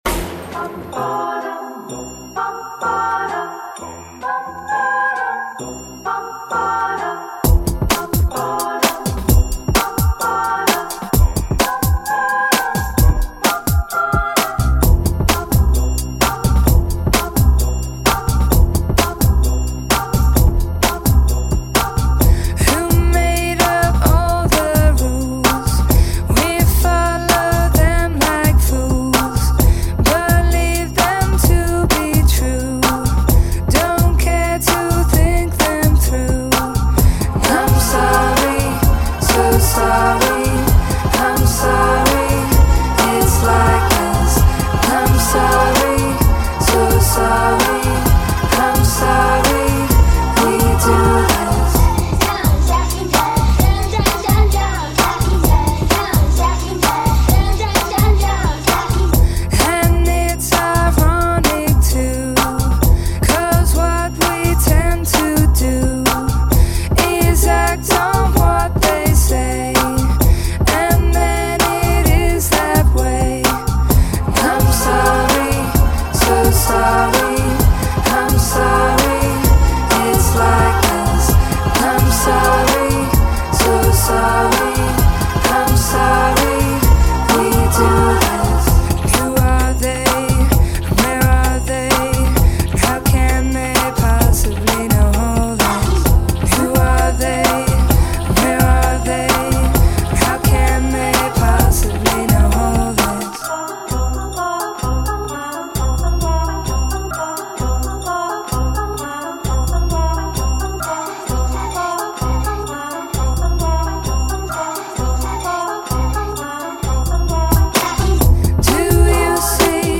Назад в (pop)...